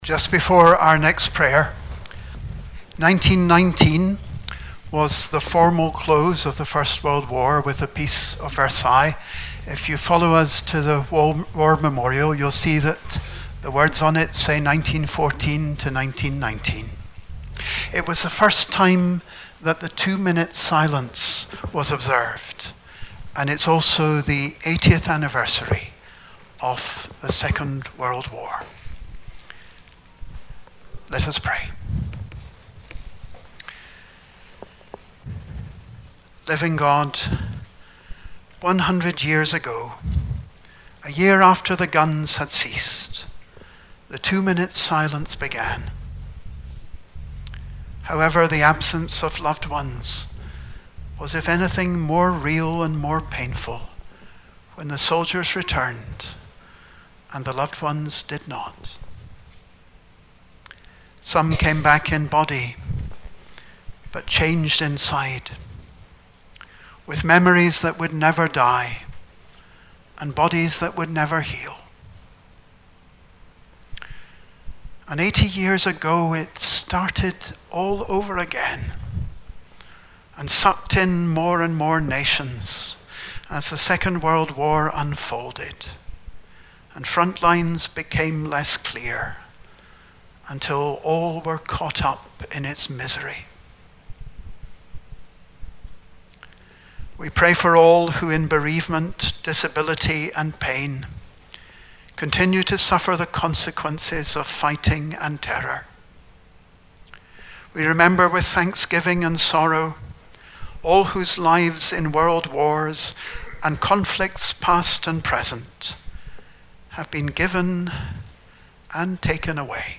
Prayers for others and ourselves, ending with the Lords Prayer'.
prayerforothersandlords.mp3